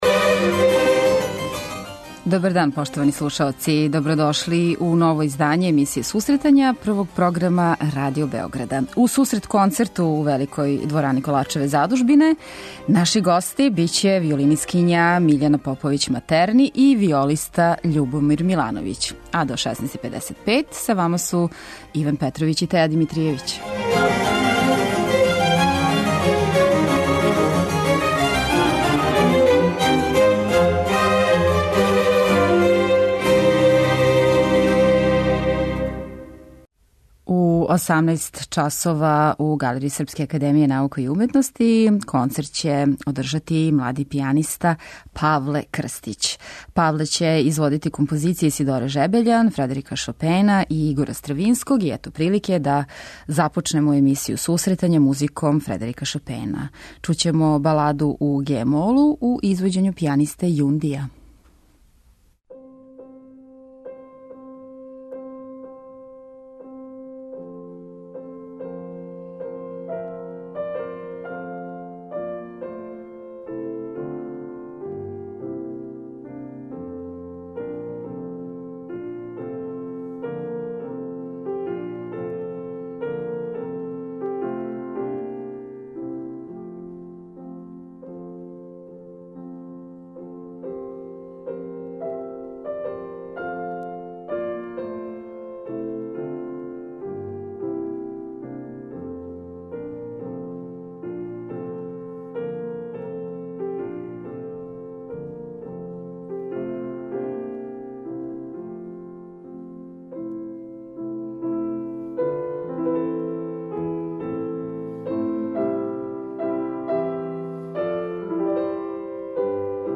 гошћа данашње емисије је виолинисткиња